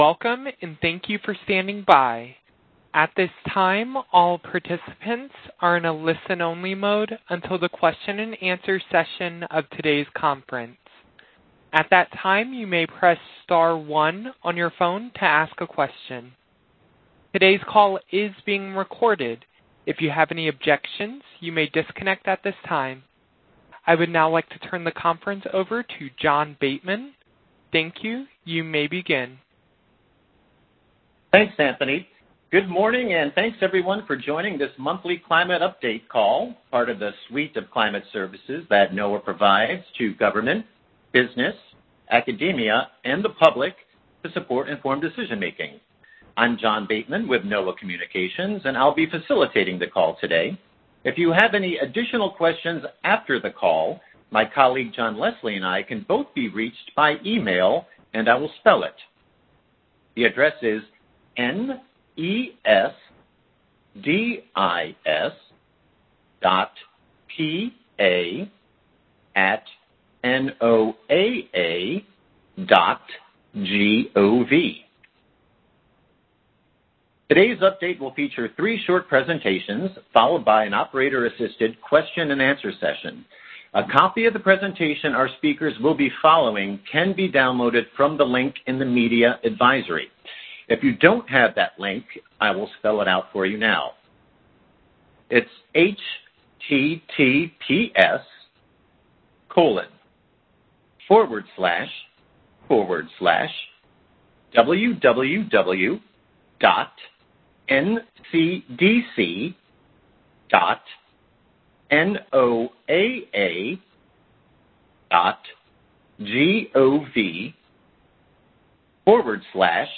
NOAA monthly U.S., global climate report call: November 19
Experts recap October and provide outlooks through February